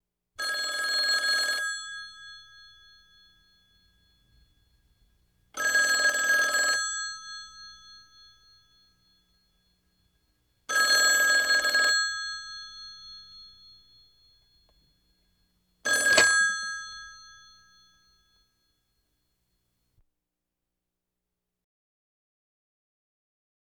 Telephone Rings Pick Up Interrupts Ring Sound Effect
telephone-rings-pick-up-interrupts-ring.wav